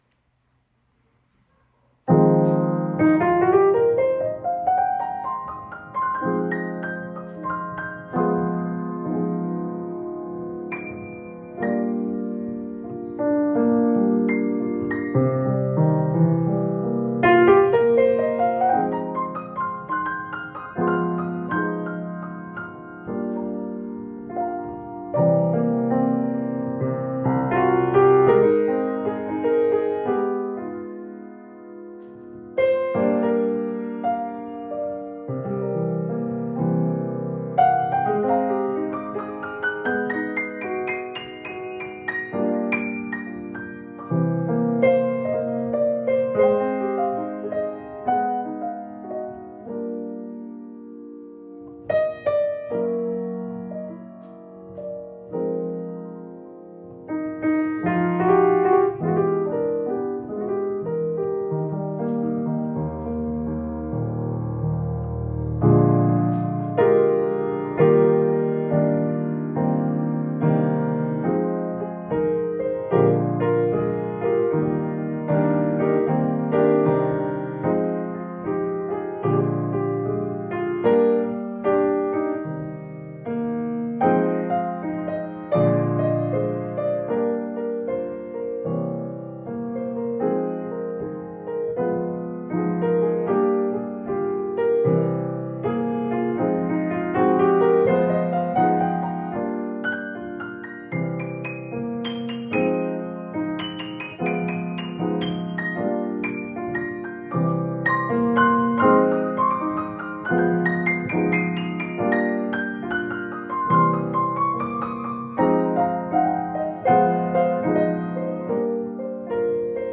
Jazz Improvisation, Odd Fellows Cocktail Hour, Davis California
the lounge lizard begins to play the piano